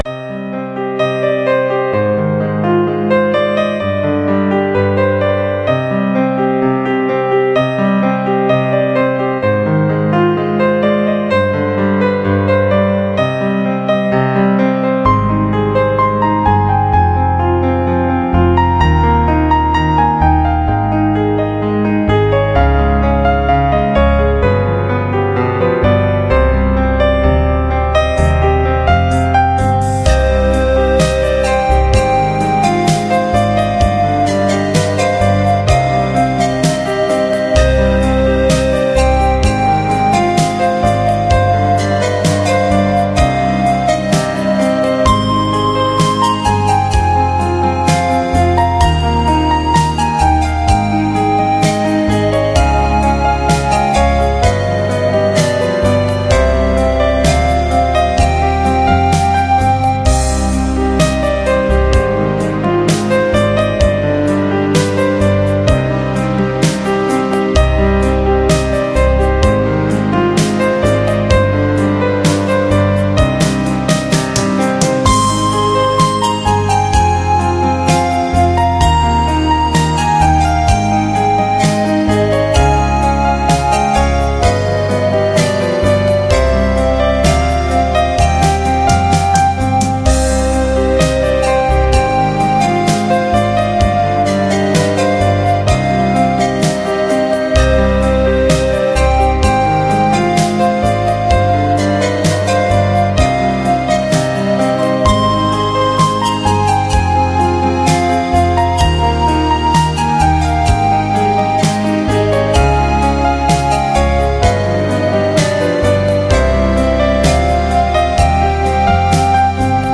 [28/3/2009]【求助】一首非常好听的轻音乐，求里面的乐器名称和曲名
[28/3/2009]【求助】一首非常好听的轻音乐，求里面的乐器名称和曲名 [media=mp3,400,300,0] [/media] 这是一首我无意中得到的轻音乐，听起来觉得让人很舒服，很好听。可惜一直不知道曲名，还有里面的乐器是不是钢琴和排箫？
还有，里面那个到底是排箫还是长笛，我还真有点搞不清楚。